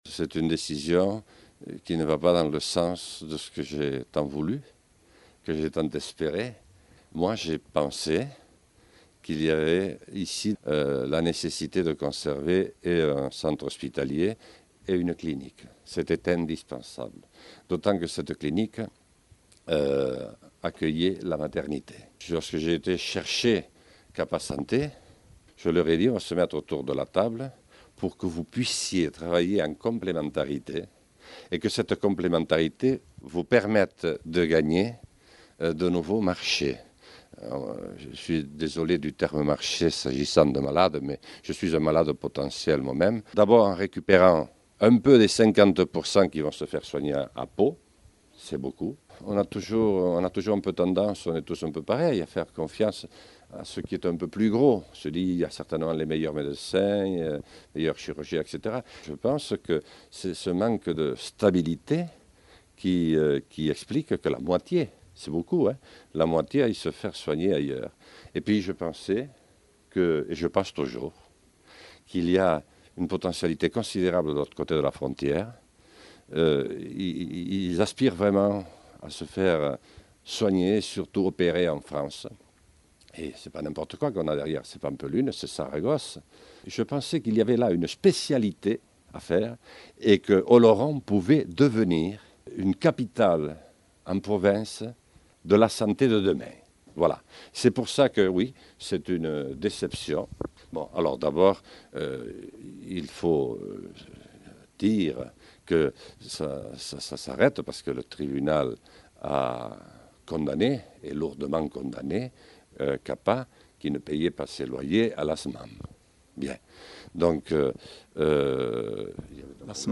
Entzün Jean Lassalle depütatüa :